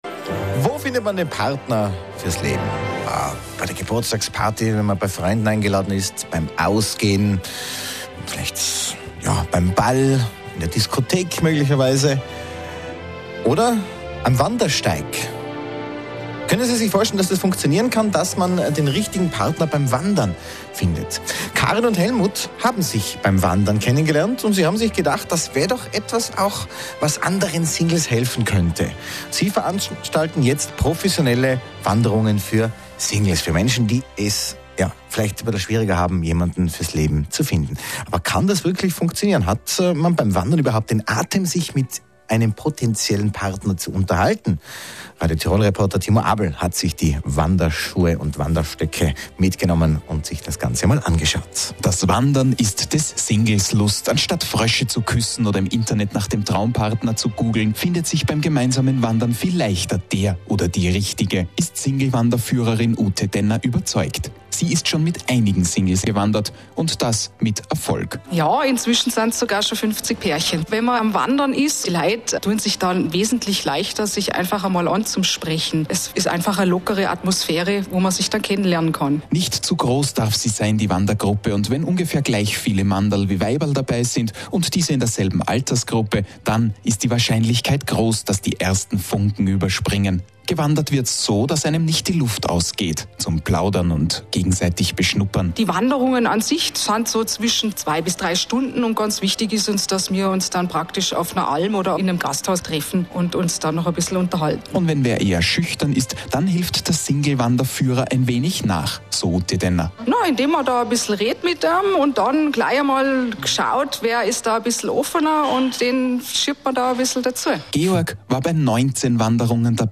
Single Wandern Tirol ORF Radio Interview